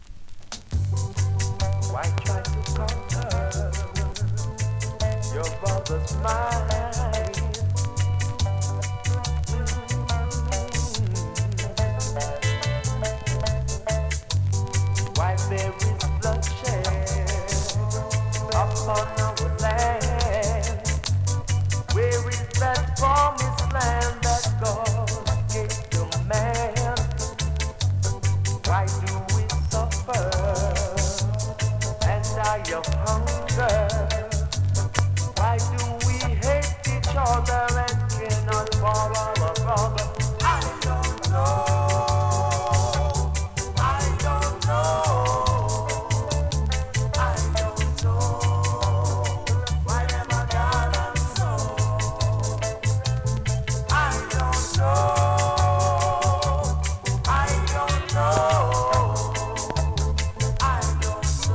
NEW IN!SKA〜REGGAE
スリキズ、ノイズ比較的少なめで